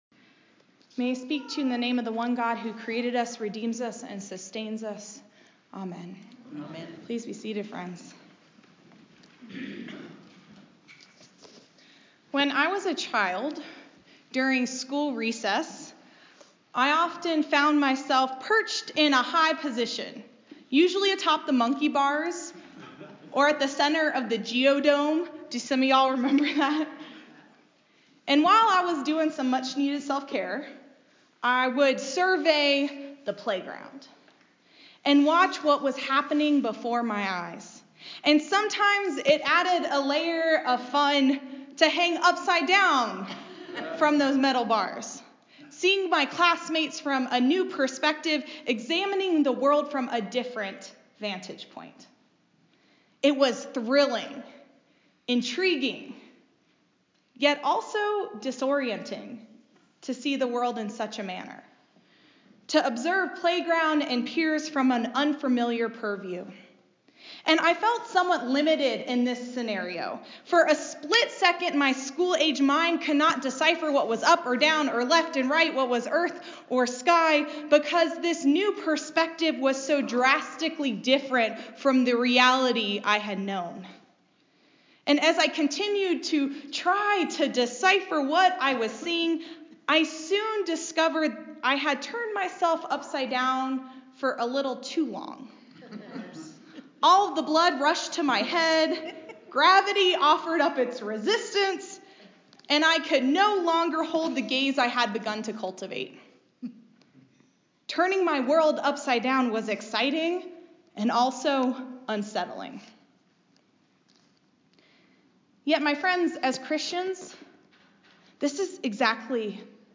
A Sermon for Year B, Proper 28